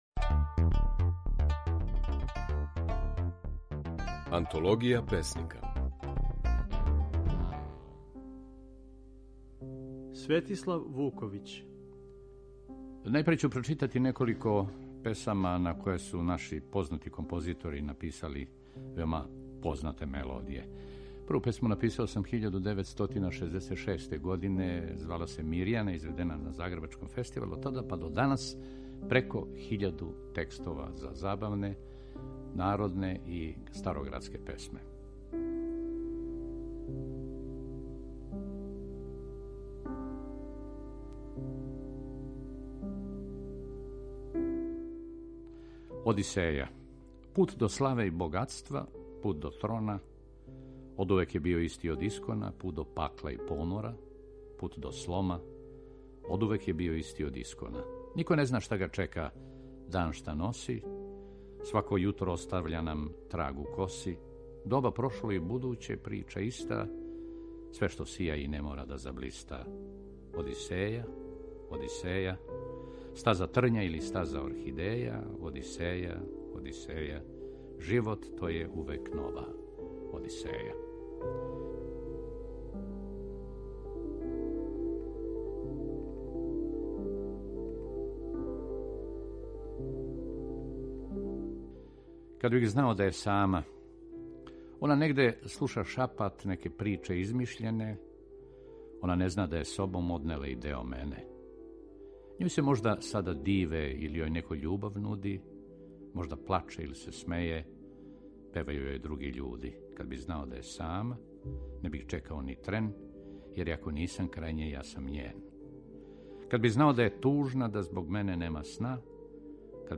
У „Антологији песника" можете чути како је своје стихове говорио Светислав Вуковић (1936-2019).